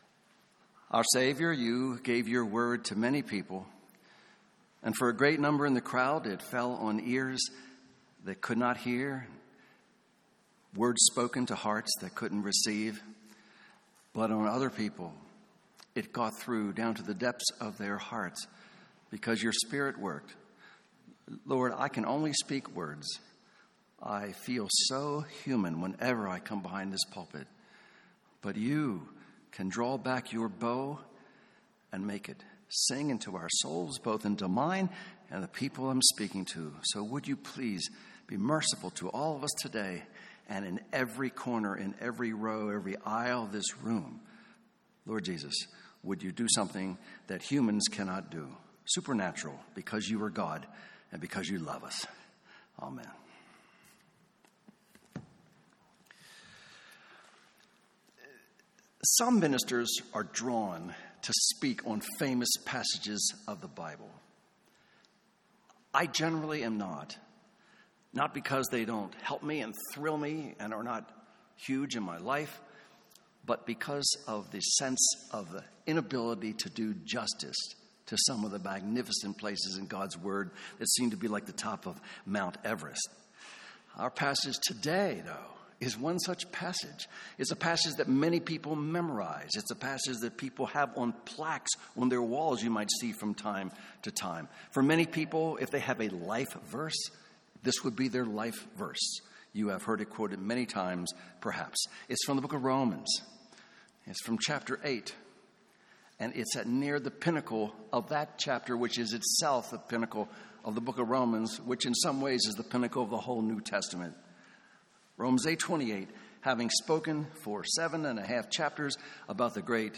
Sermons on Romans 8:28 — Audio Sermons — Brick Lane Community Church